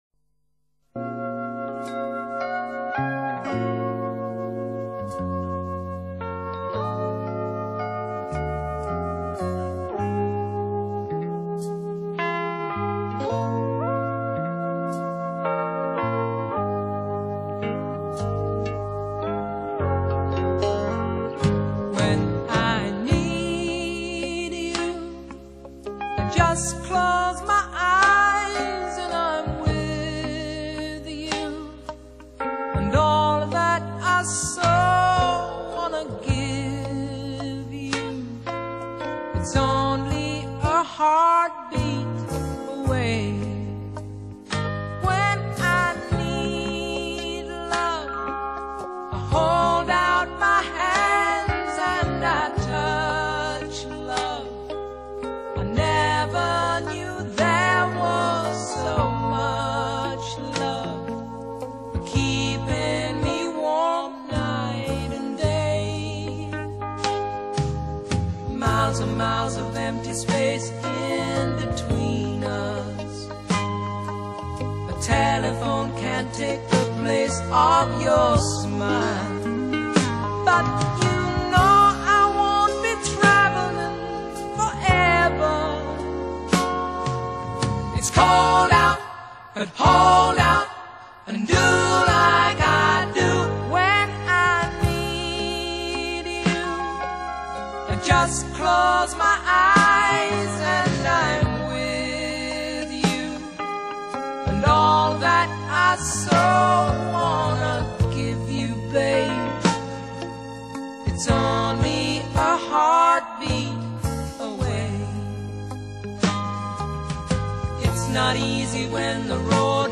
Genre: Love ballads, pop, rock